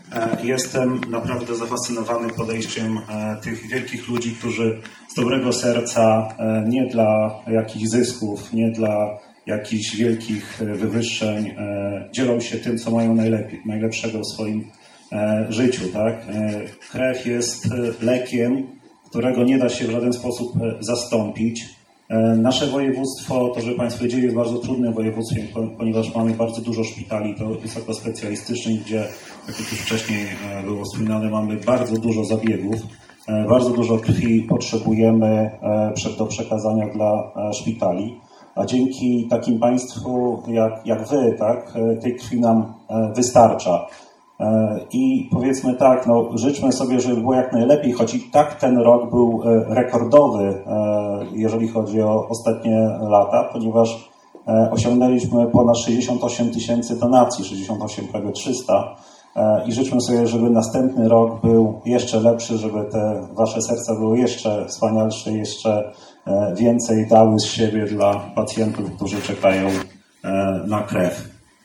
Podczas uroczystego spotkania krwiodawców w Majdanie Starym podsumowano całokształt działalności nie tylko lokalnego, ale i wszystkich klubów działających w powiecie.